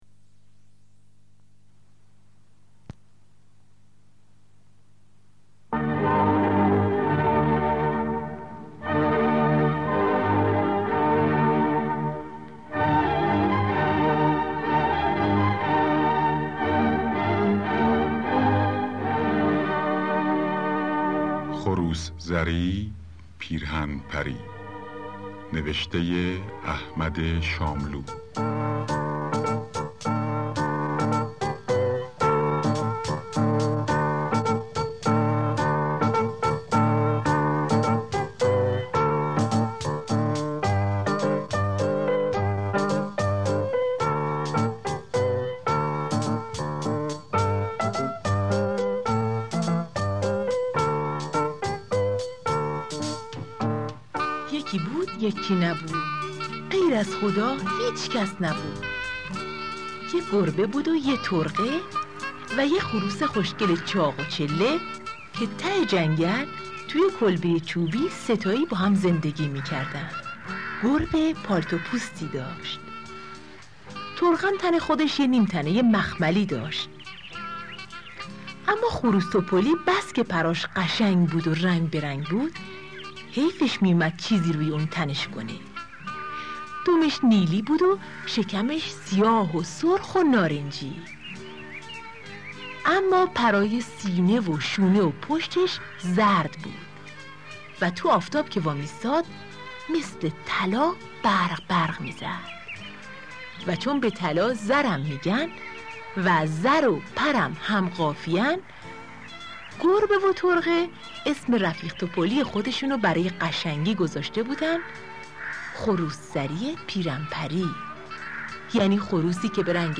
پیرهن پری پخش آنلاین دانلود نسخه صوتی دکلمه دانلود / گوینده: [احمد شاملو] برچسب ها: احمد شاملو خروس زری .